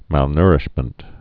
(mălnûrĭsh-mənt)